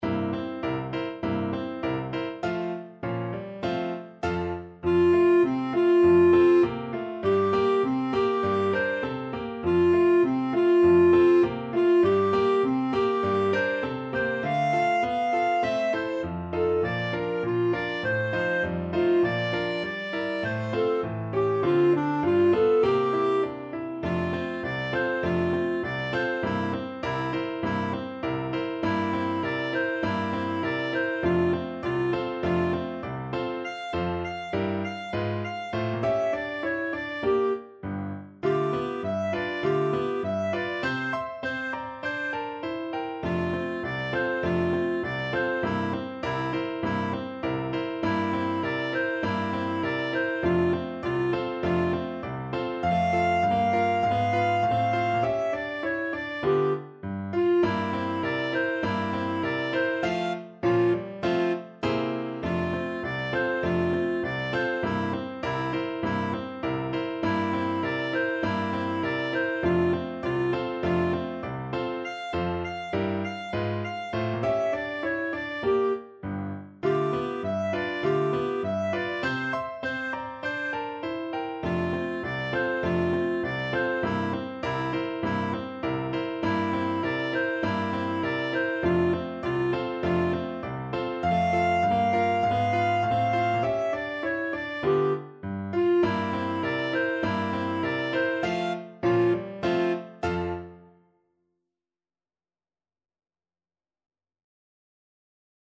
Clarinet version
Moderato =c.100
Pop (View more Pop Clarinet Music)